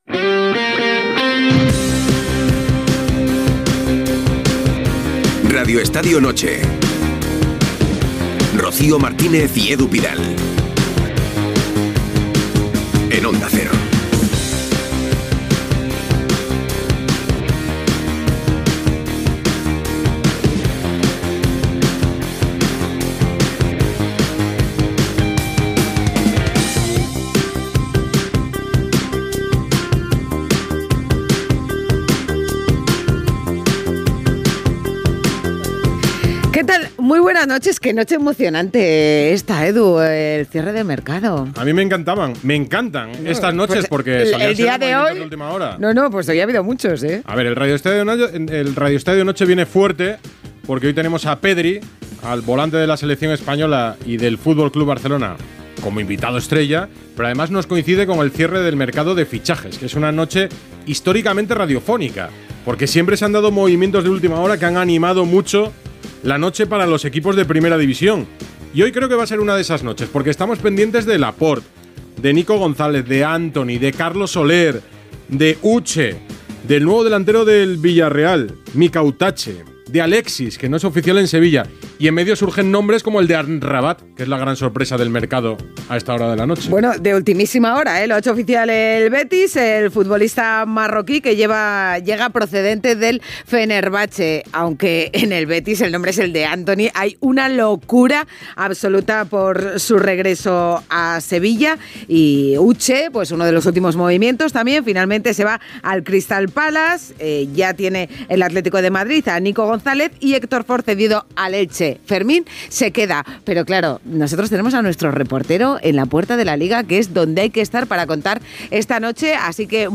Careta, inici del programa el dia del tancament de fixatges a la seu dela Lliga de Futbol Professional. Connexió amb la unitat mòbil a la seu de la Lliga
Esportiu